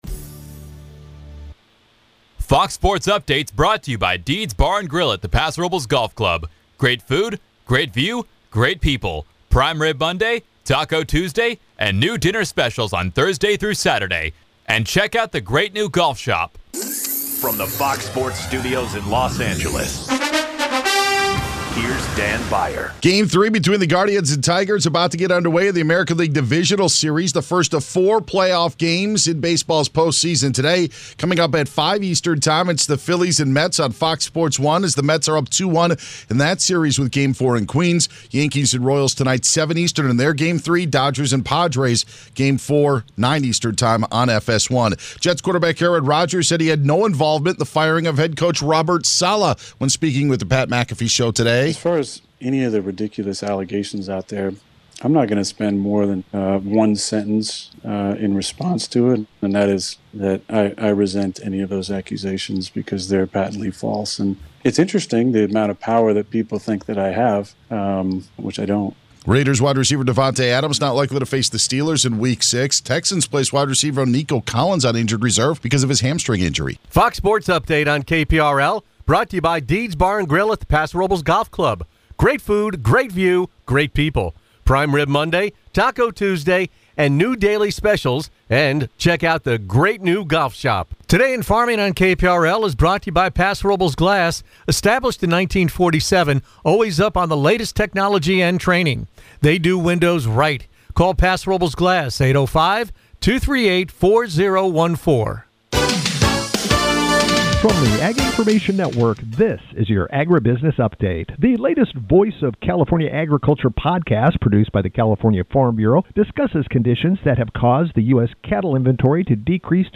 Tune in to the longest running talk show on the Central Coast – now in its sixth decade.